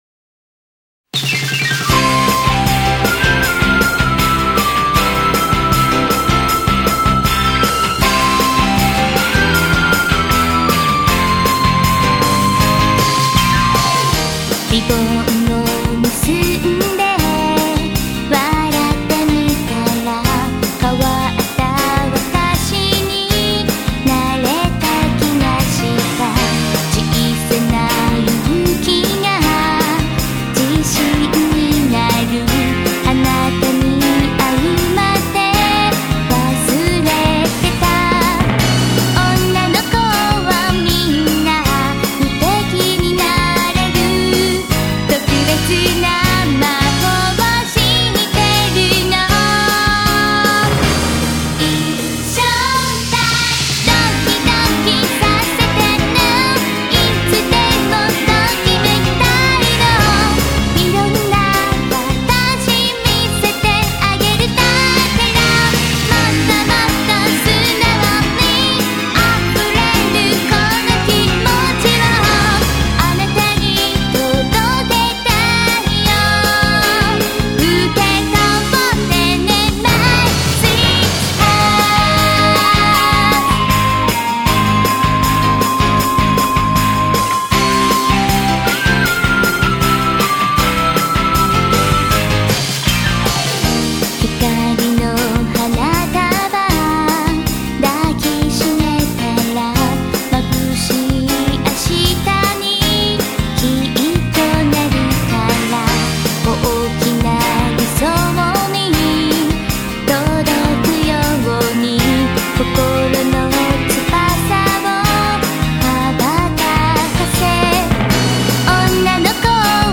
Sigle Originali Giapponesi: